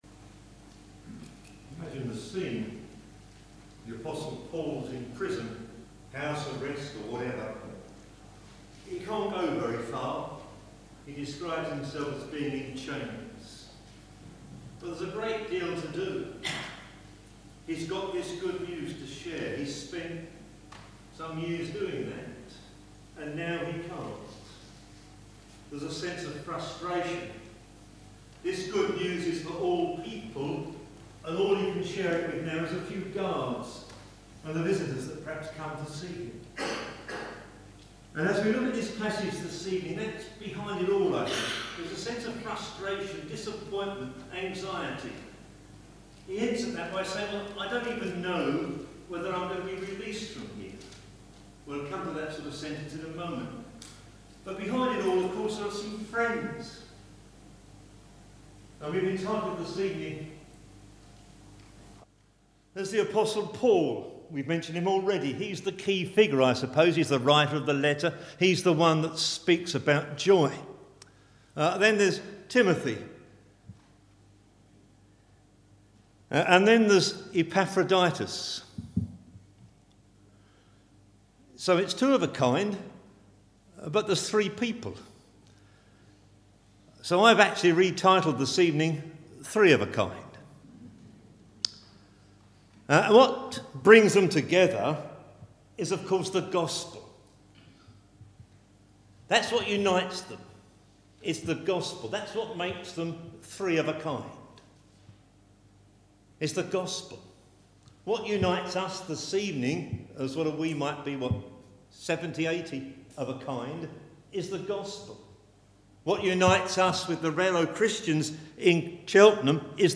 Bible Text: Philippians 2:19-30 | Preacher